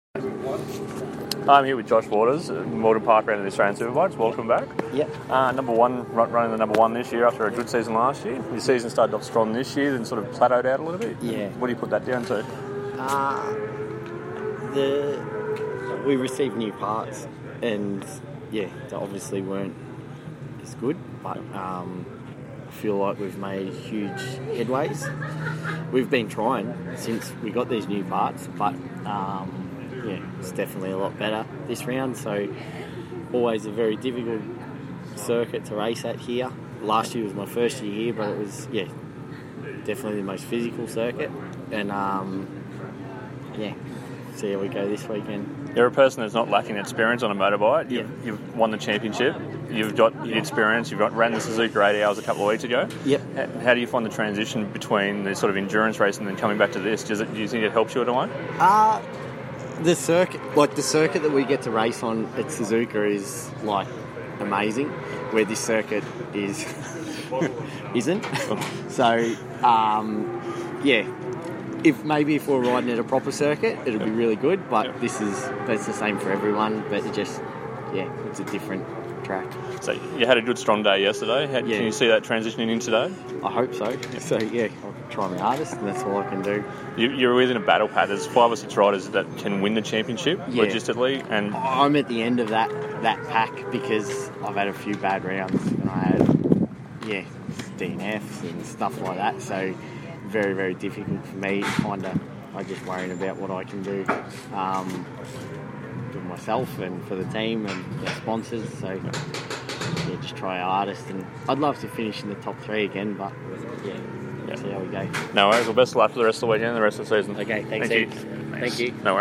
ASBK: We catch up with Suzuki’s Josh Waters at Morgan Park | RacerViews